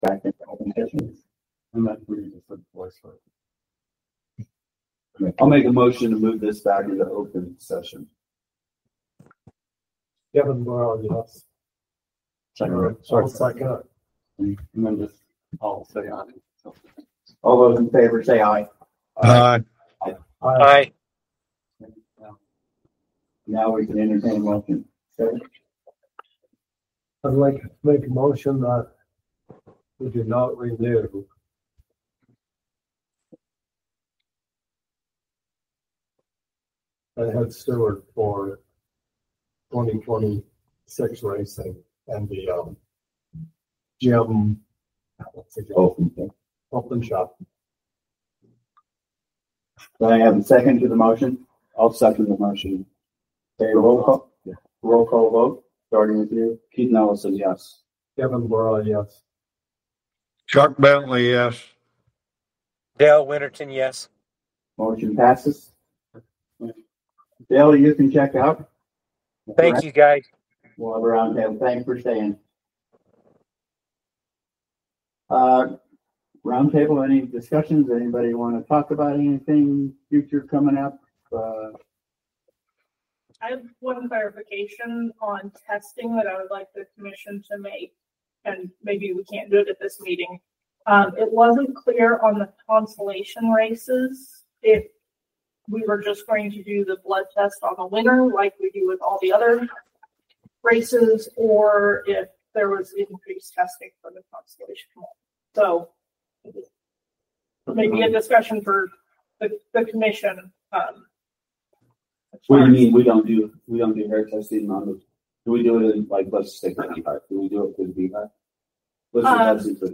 Utah Horse Racing Commission Meeting